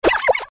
SE 宇宙人の声 02
/ F｜演出・アニメ・心理 / F-75 ｜other 生音の再現 / 50_other_鳴き声